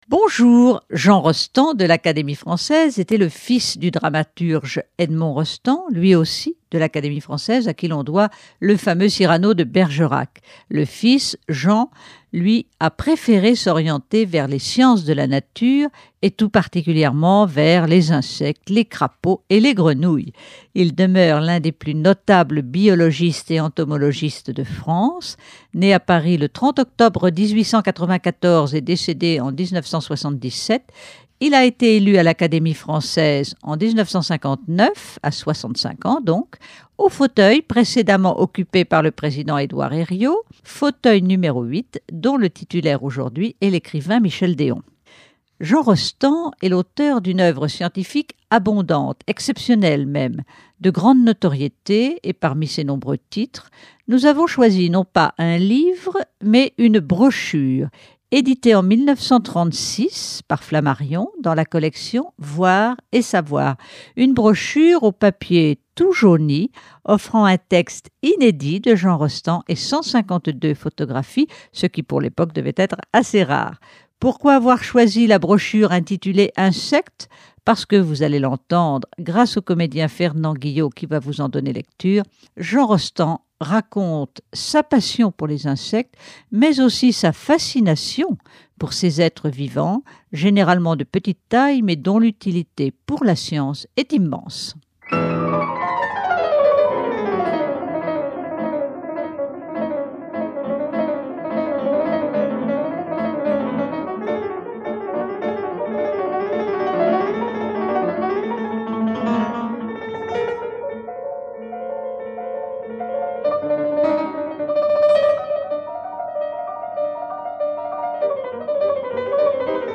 Lecture d’extraits de sa brochure illustrée publiée en 1936 : Insectes Jean Rostand, élu à l’Académie française en 1959, avait pour passion les insectes.